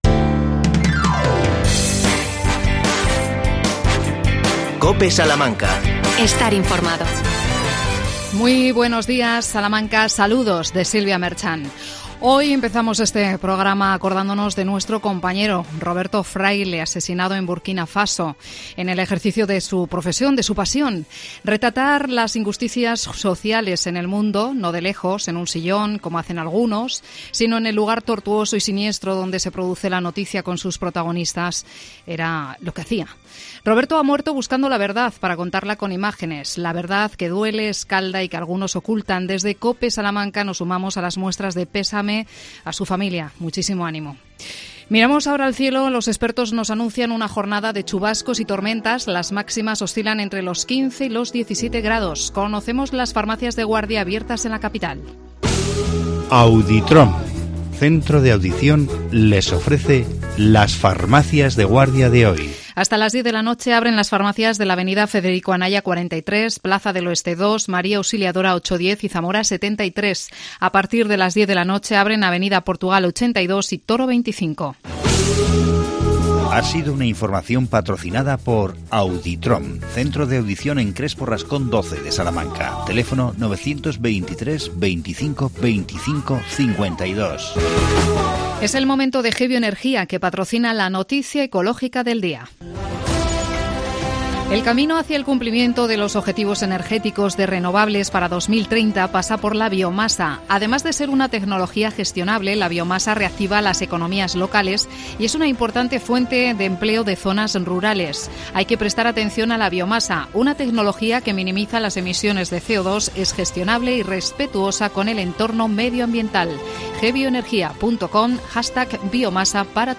AUDIO: Entrevista al Concejal de Régimen Interior en el Ayuntamiento de Salamanca,Fernando Rodríguez.El tema: la oferta pública de empleo municipal.